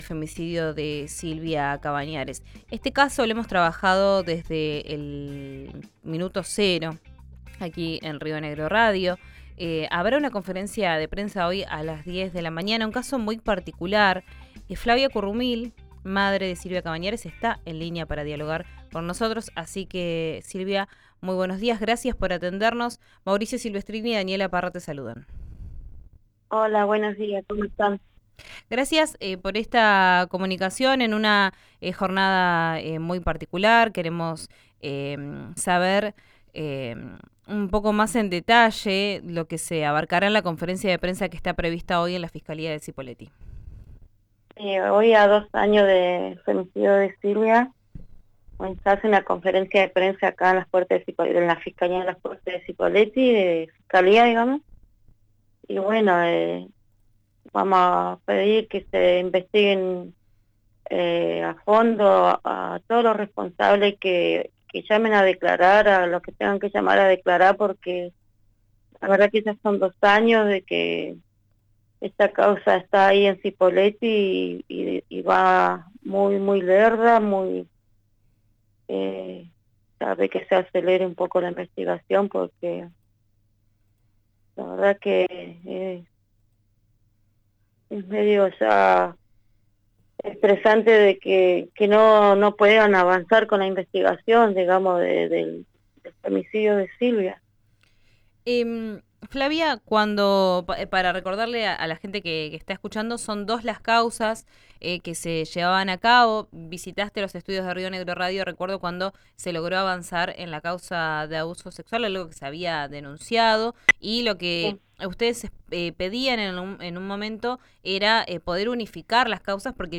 «Pedir que se investiguen a fondo a todos los responsables. Que llamen a declarar a todos los que tengan que hacerlo. Ya son dos años de que esta causa está en Cipolletti y está muy lenta. Que se acelere la investigación», señaló en diálogo con «Vos al aire».